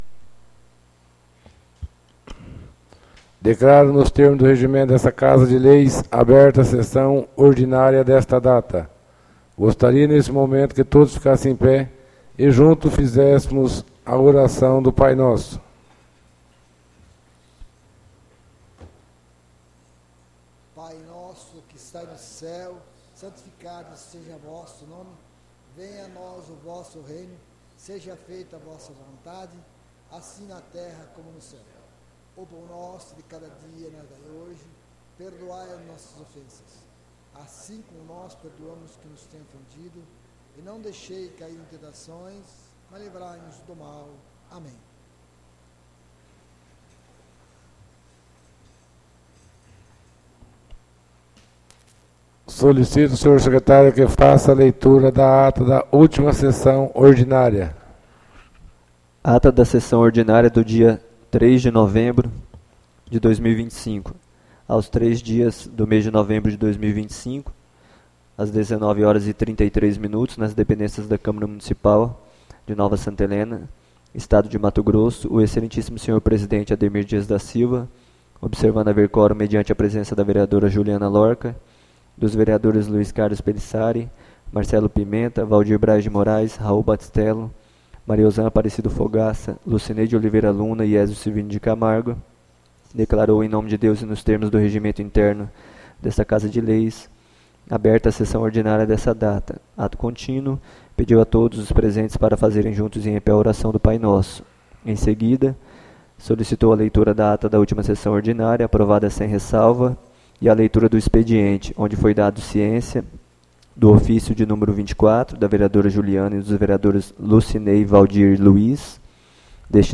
ÁUDIO SESSÃO 10-11-25 — CÂMARA MUNICIPAL DE NOVA SANTA HELENA - MT